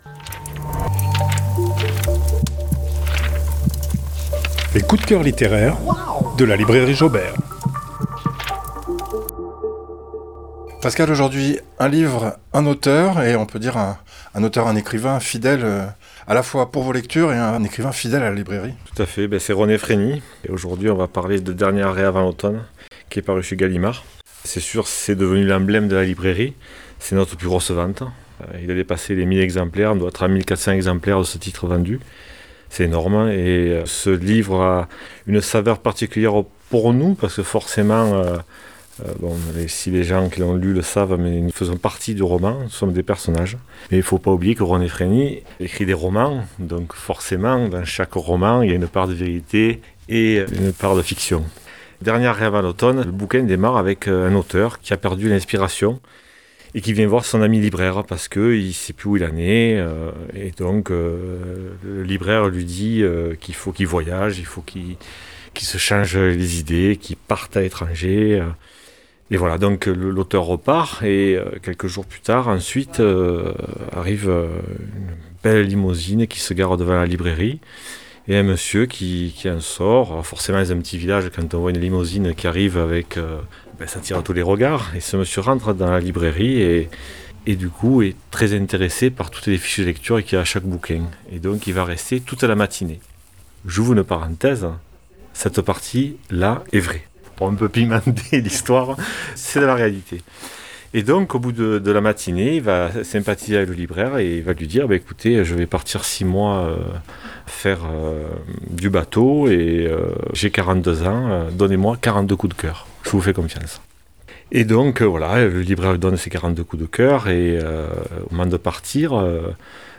Une chronique hebdomadaire, à retrouver chaque jeudi sur Fréquence Mistral dans le "Mag du Midi".